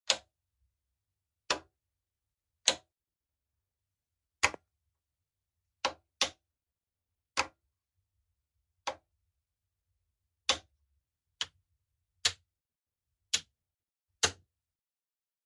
GUITAR AMPLIFIER CLICKS
guitar-amplifier-clicks-27038.mp3